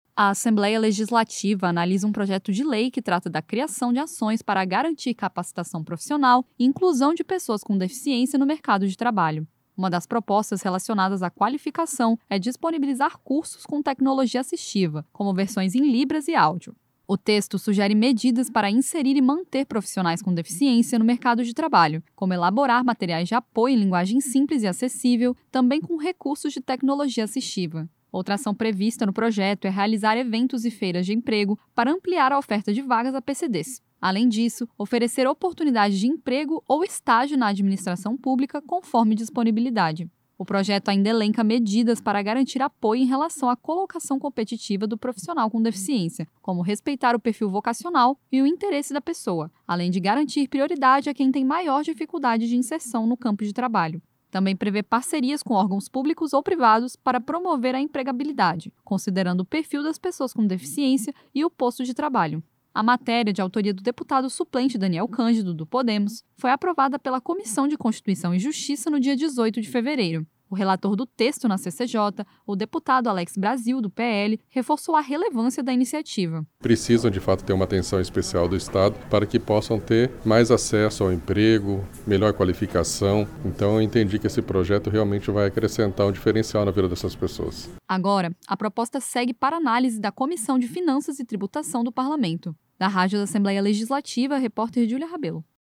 Entrevista com:
- deputado Alex Brasil (PL), relator do PL 55/2024 na Comissão de Constituição e Justiça (CCJ).